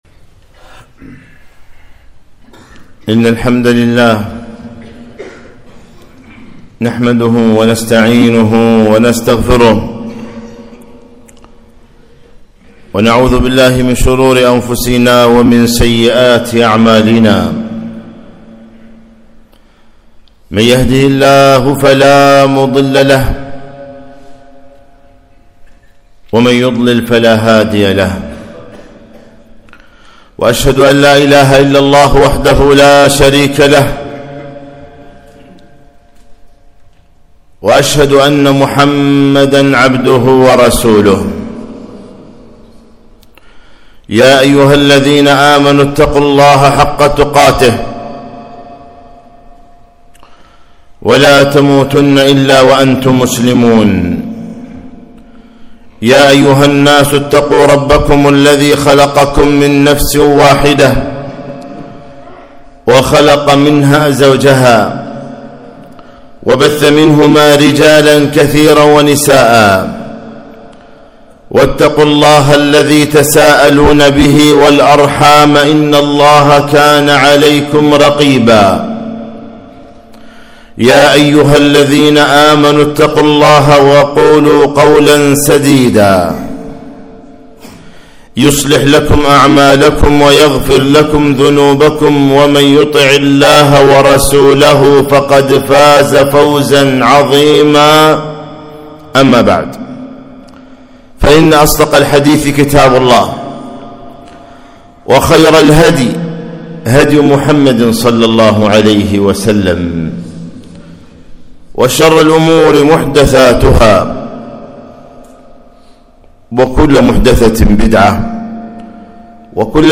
خطبة - ( إذا سألت فاسأل الله)